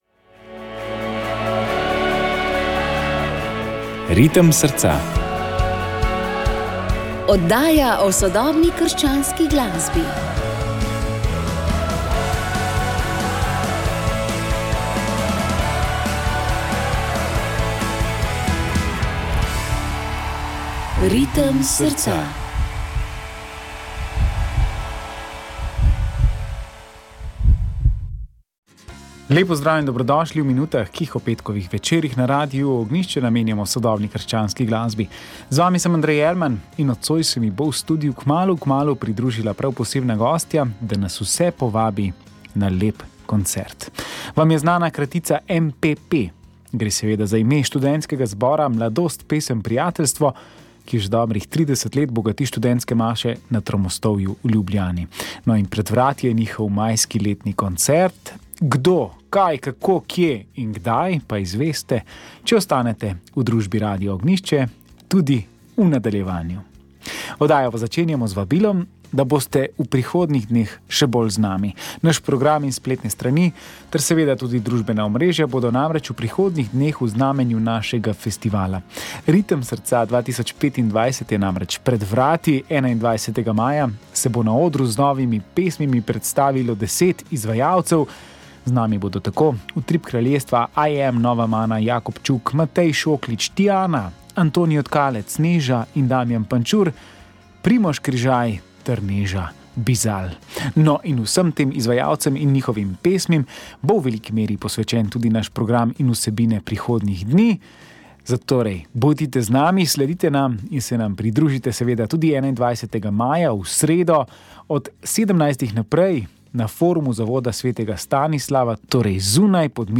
sodobna krščanska glasba